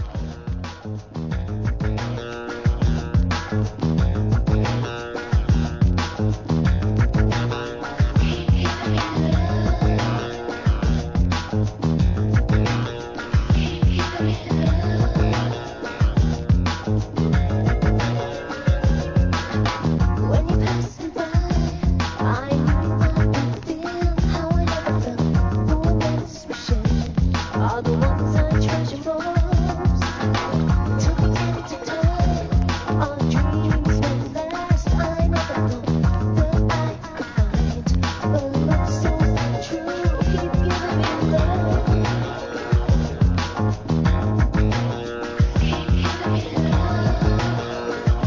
HIP HOP/R&B
浮遊感のある打ち込みサウンドにフィメール・ヴォーカルをfeat.した1992年リリース!